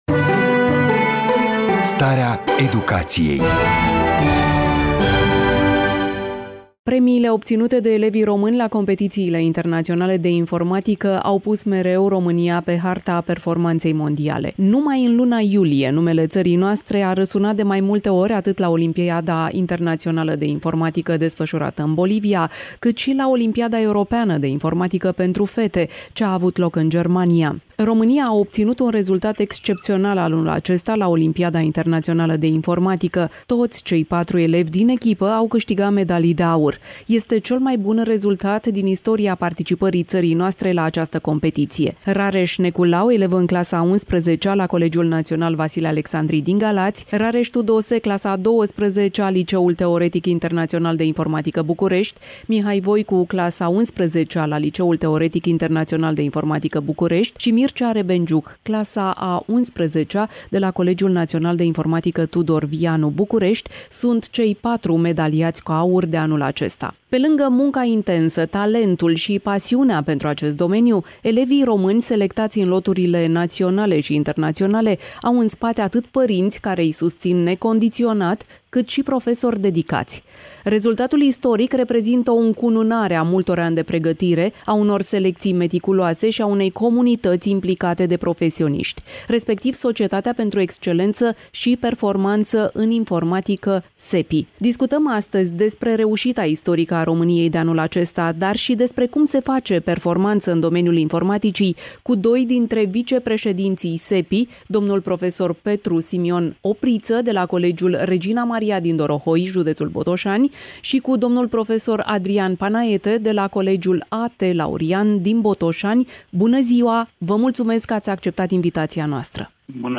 Varianta audio a interviului: Share pe Facebook Share pe Whatsapp Share pe X Etichete: informatica radio iasi starea educatiei